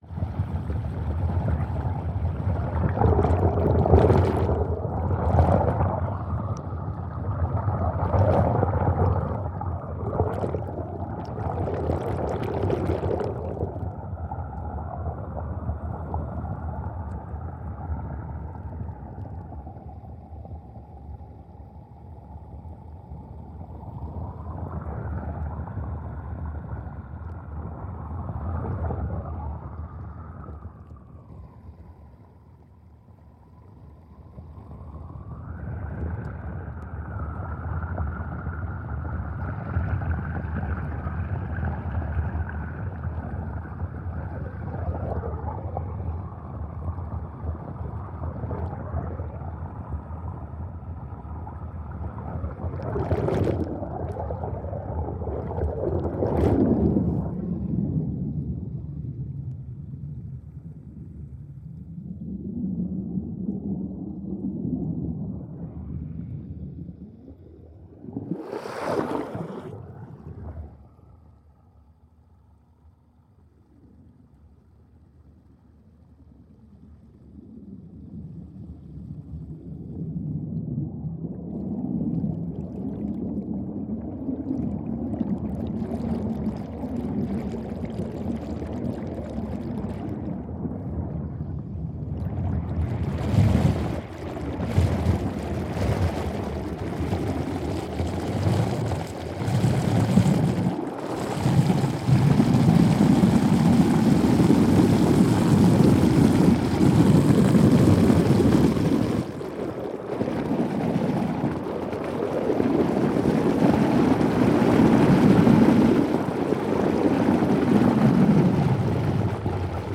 Ambience Aquatic Sound Of A Water Flow In A Swimming Pool.ogg